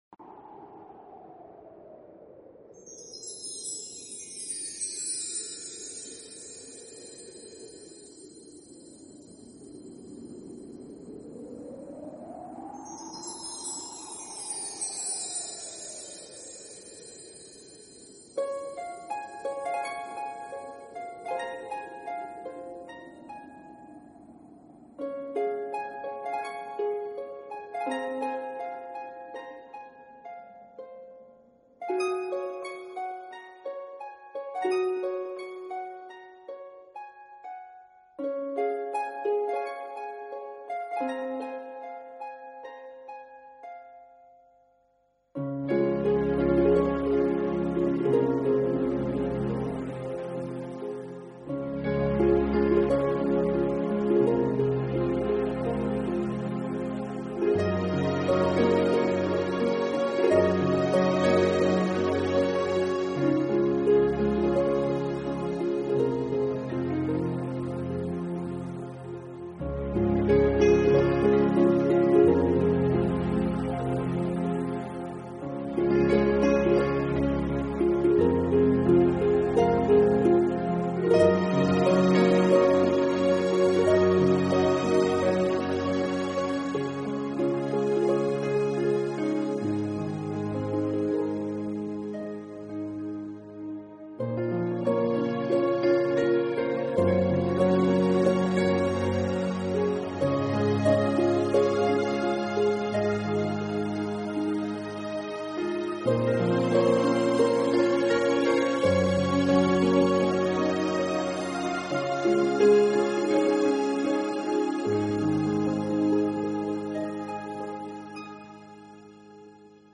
Genre..........: New Age
QUALiTY........: MP3 44,1kHz / Stereo
helps create a relaxing and inspiring atmosphere.